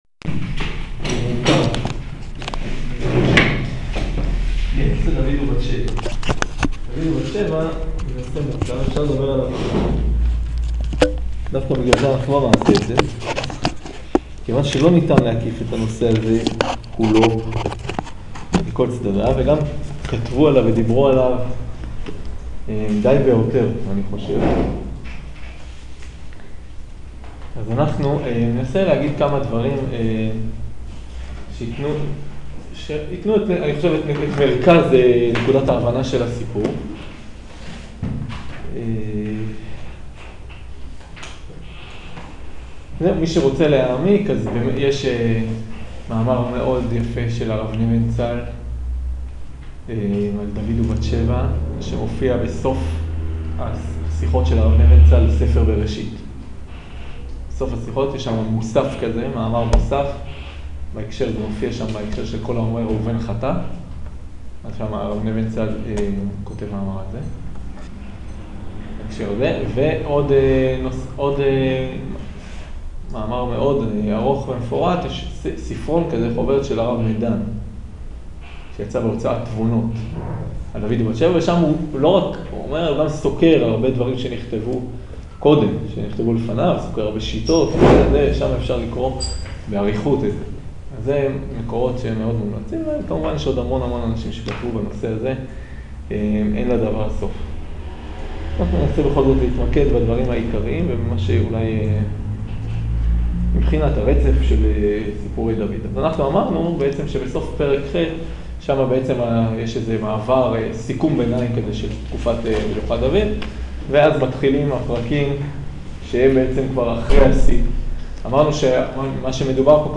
שיעור שמואל ב' פרק י"א